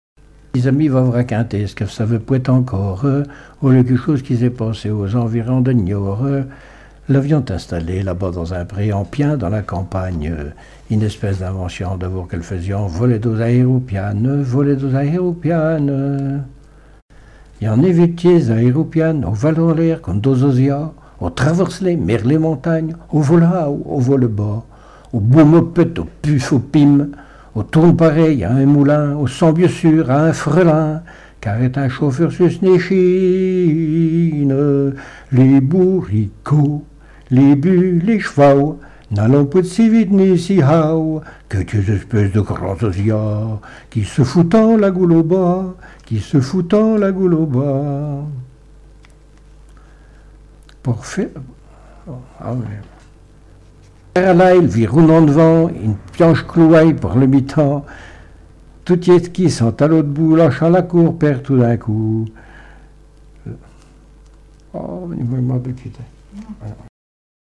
Témoignages et chansons
Pièce musicale inédite